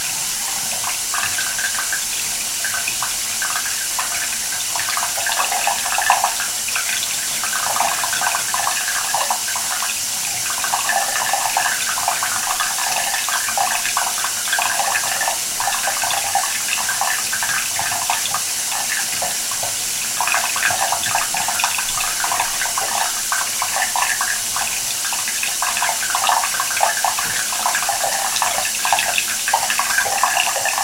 洗澡时 "水汩汩地流在浴缸的溢流孔里满满的版本
描述：浴缸溢流孔里的水汩汩流出。完整版。
标签： 溢流孔 潺潺
声道立体声